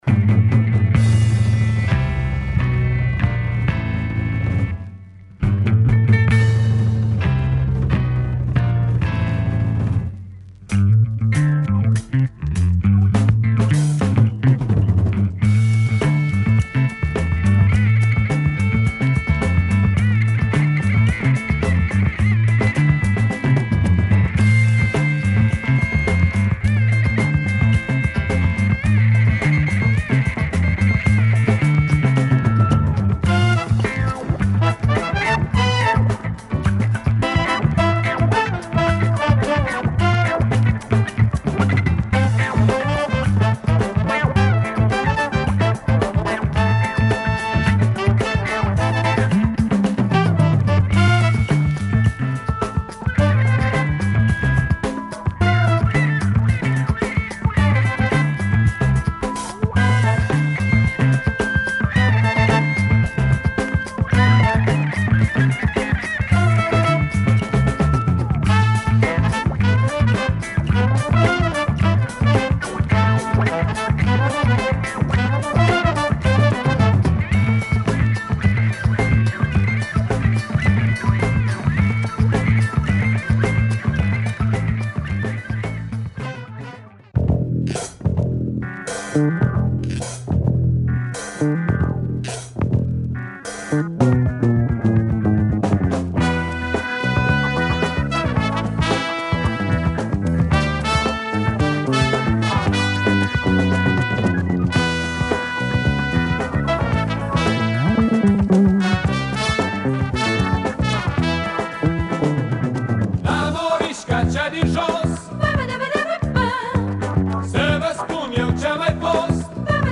Yes, Moldovian funk !
Tremendous soviet funk !